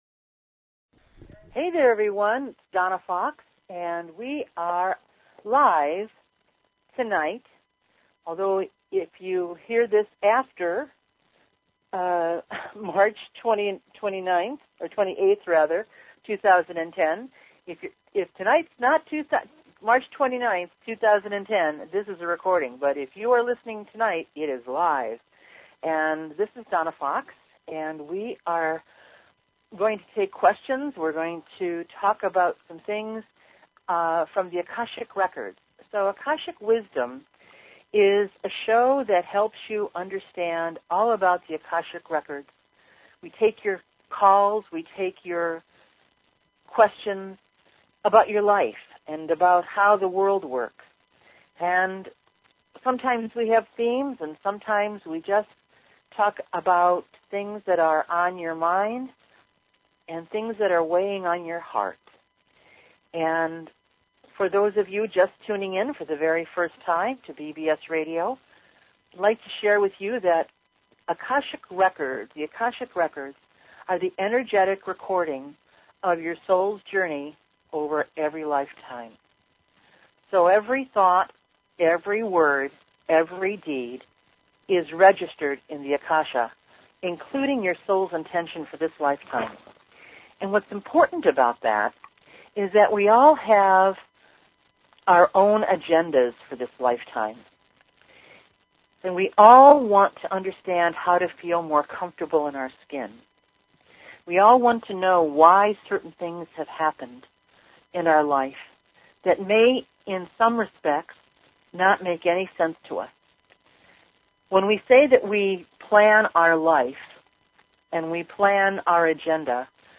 Talk Show Episode, Audio Podcast, Akashic_Wisdom and Courtesy of BBS Radio on , show guests , about , categorized as